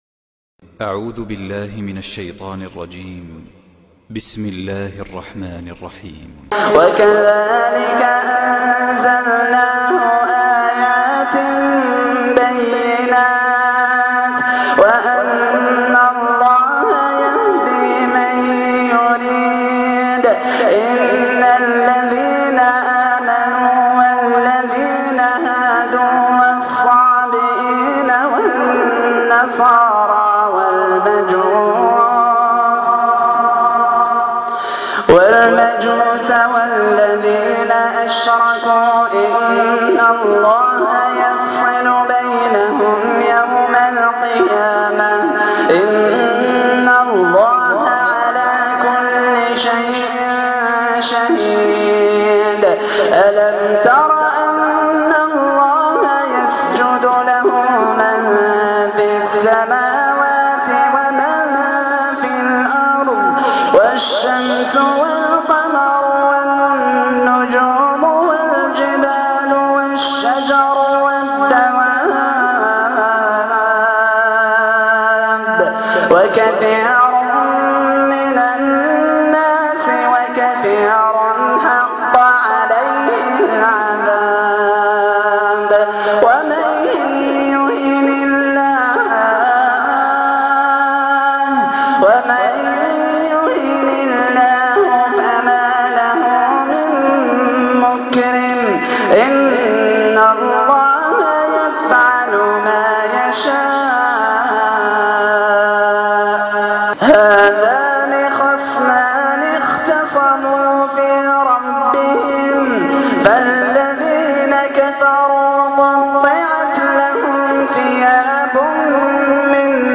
قراءة بنبرة حزينة ومؤثرة